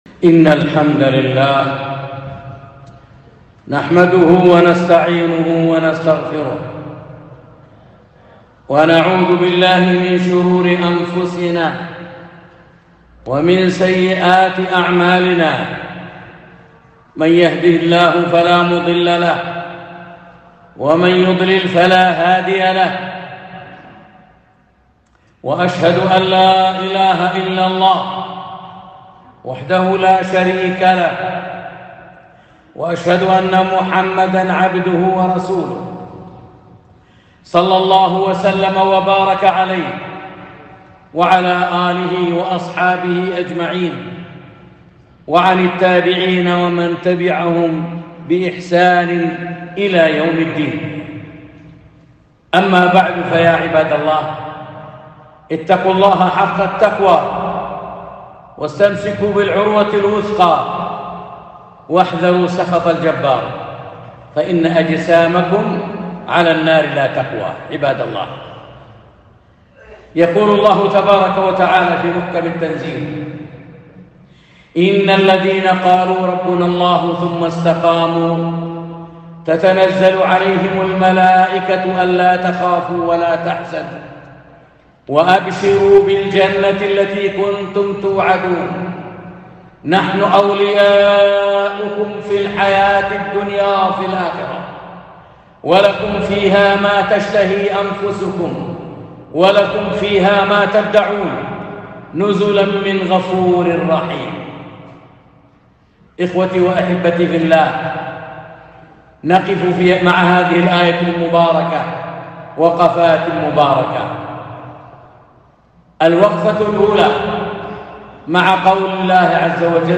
خطبة - الاستقامة